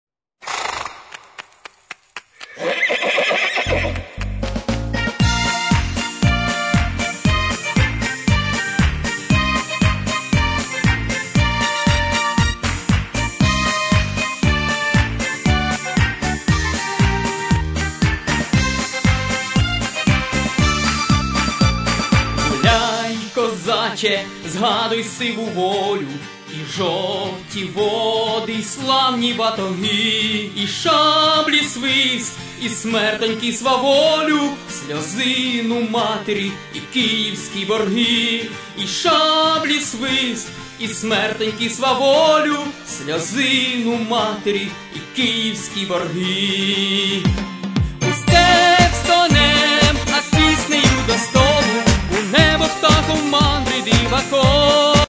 Народна (248)